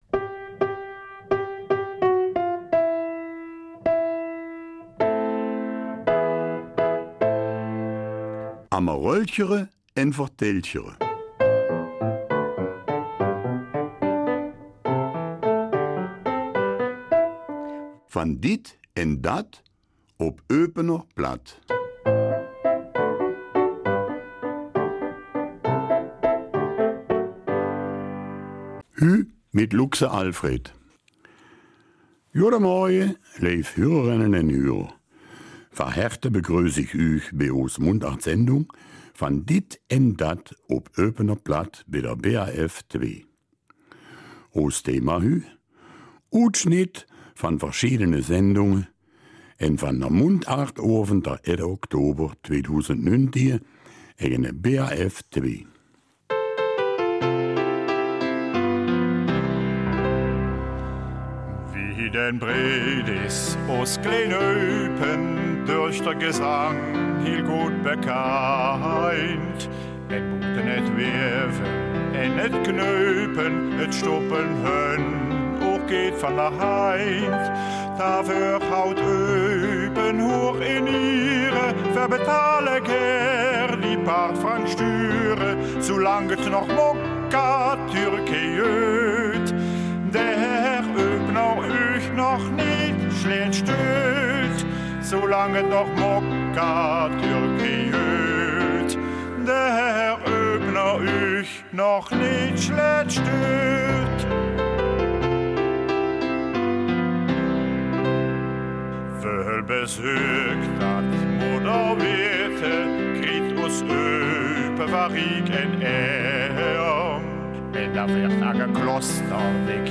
Eupener Mundart – 24. November